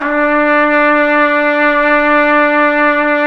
Index of /90_sSampleCDs/Roland L-CDX-03 Disk 2/BRS_Trumpet 1-4/BRS_Tp 3 Ambient